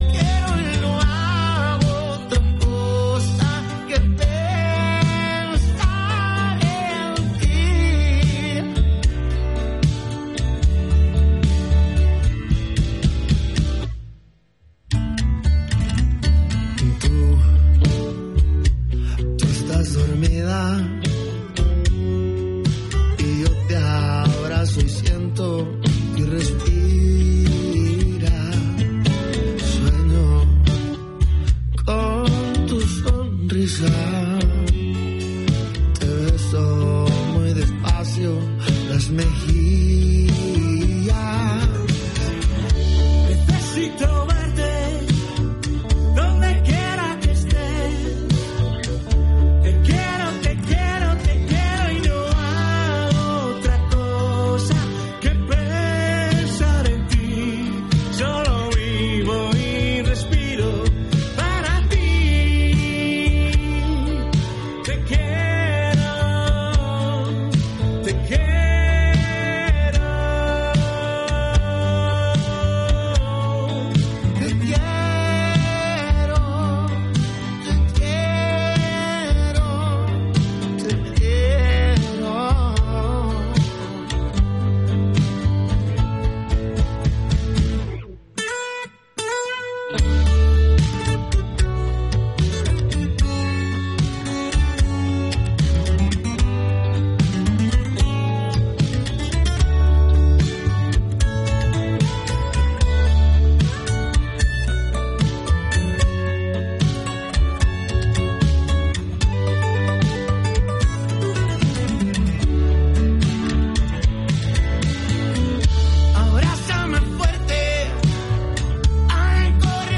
1 Amb G de Gavà del 3/12/2024 1:00:00 Play Pause 1d ago 1:00:00 Play Pause Na później Na później Listy Polub Polubione 1:00:00 "Amb G de Gavà" és el magazín matinal de Ràdio Gavà (91.2 FM). De dilluns a divendres, de 10 a 13 hores, posem l'accent en la informació local i els seus protagonistes, que podeu ser vosaltres, ciutadans i ciutadanes de Gavà! Cultura, associacionisme, salut, jocs de taula, música, pagesia i més.